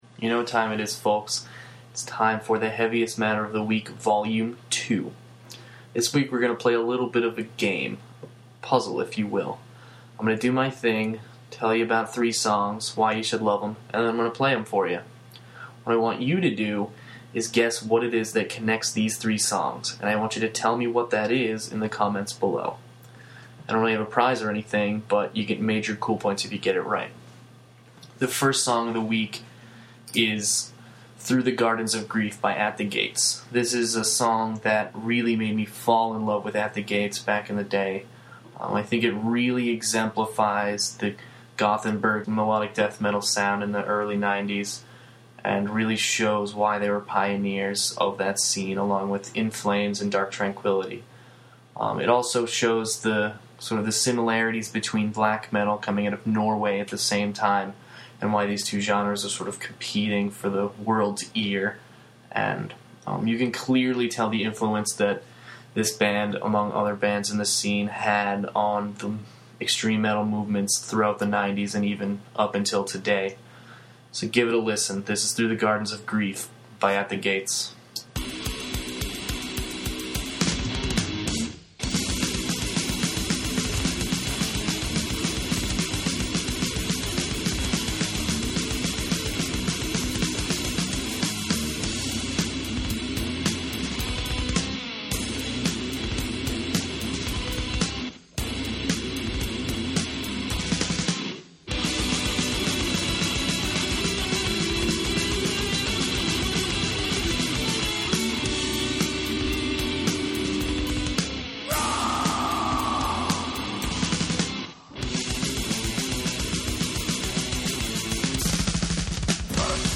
Well, it’s a weekly audio feature in which I will preview a few tracks, tell you a little about them, and provide you with an audio sample.